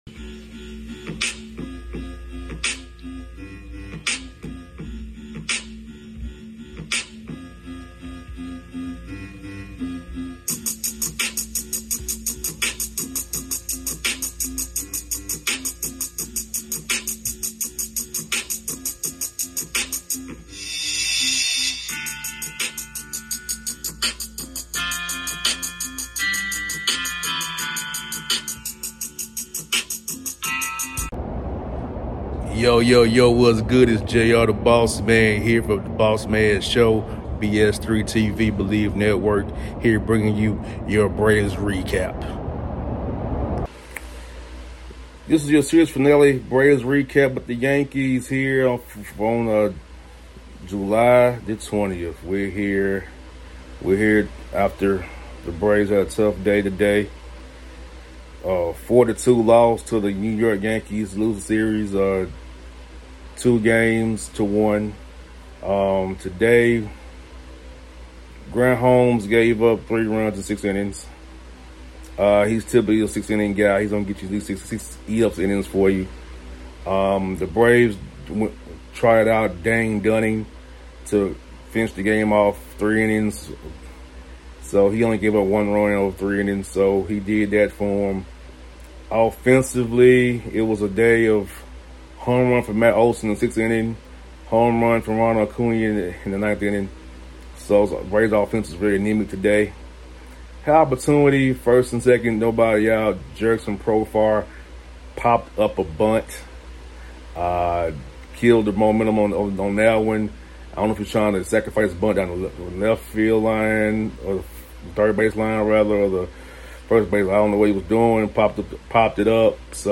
07-20-25 Atlanta Braves Postgame Recap featuring Pitcher Grant Holmes and Manager Brian Snitker
Braves lose 4-2 to the New York Yankees at Truist Park. Braves Game 98 (43-55) WP: Stroman (2-1) LP: Holmes (4-9) SV: Williams (14) Attendance: 40,125 (Sellout) In addition to my thoughts on the game listen to postgame comments from Pitcher Grant Holmes and Manager Brian Snitker.